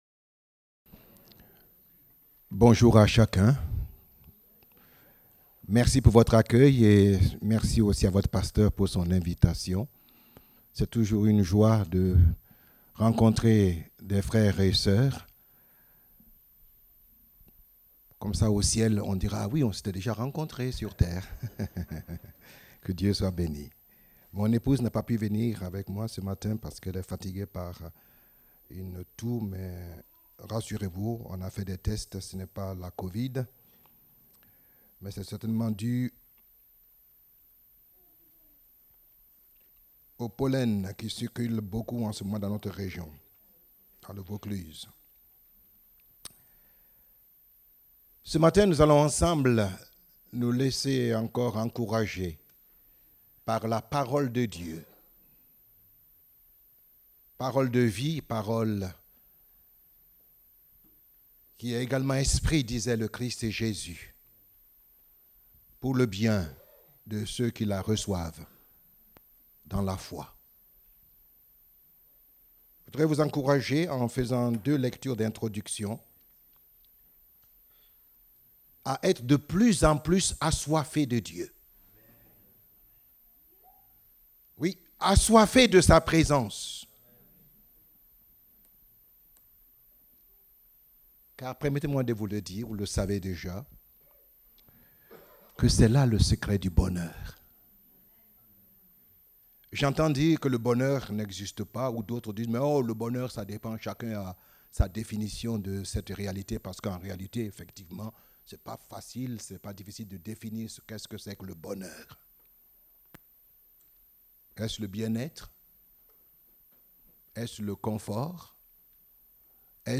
Date : 14 mai 2023 (Culte Dominical)